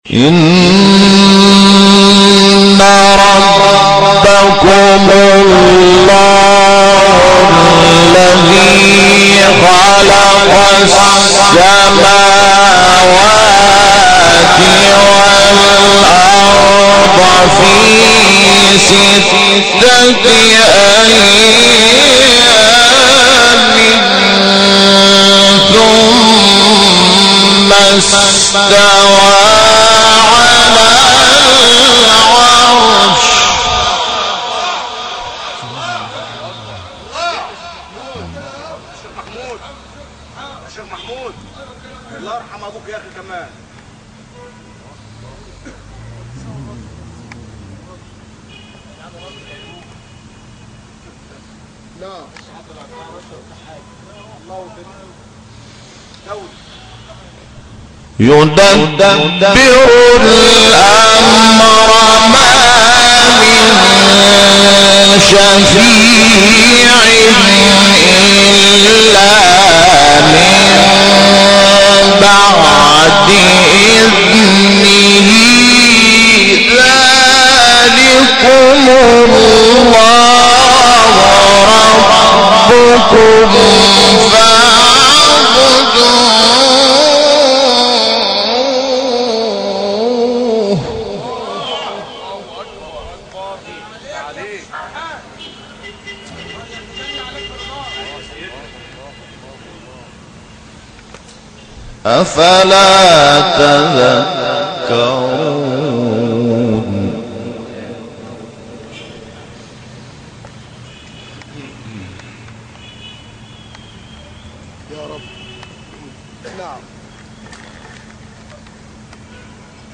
سوره : توبه – یونس آیه : (128) – (1) استاد : محمود شحات مقام : رست قبلی بعدی